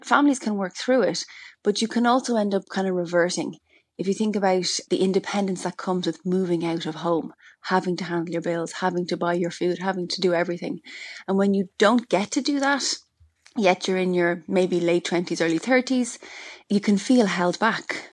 That’s the opinion of a Senior Clinical Psychologist who says independence plays a huge role in people’s development.